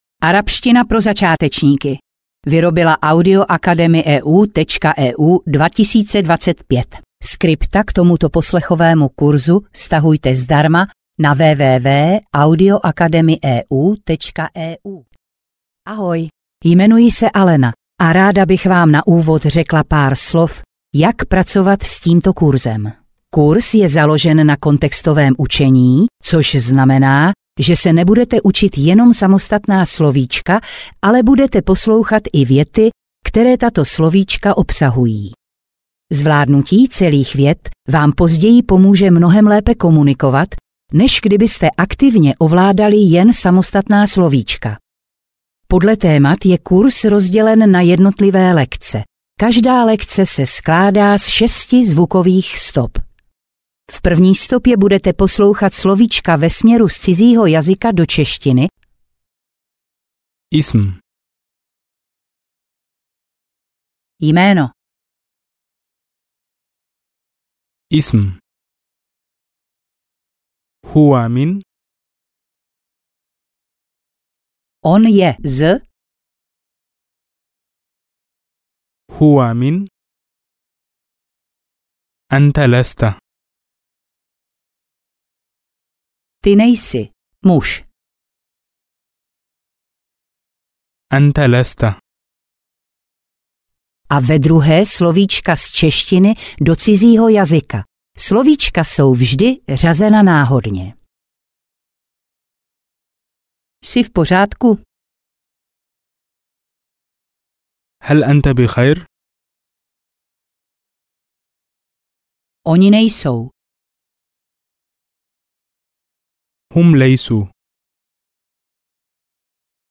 Arabština pro začátečníky audiokniha
Ukázka z knihy